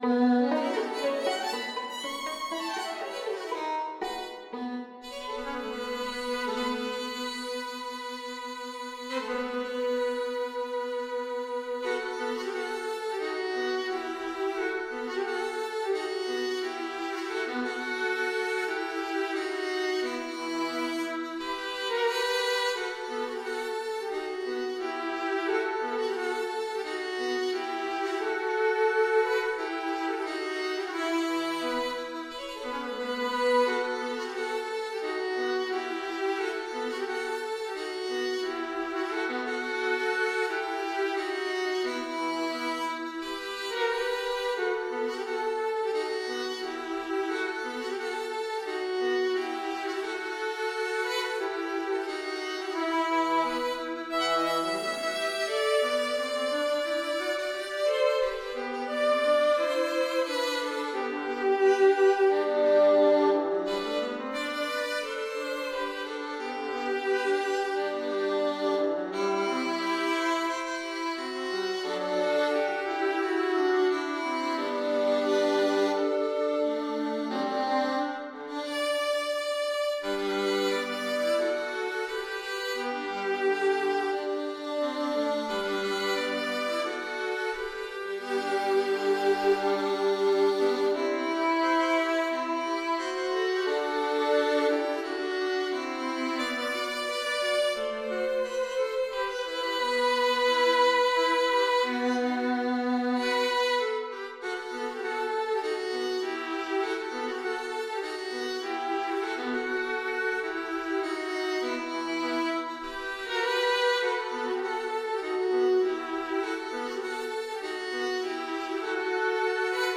The upbeat dance of the setting is not a lullaby, however.
3 pages, circa 3' 15" - an MP3 demo is here: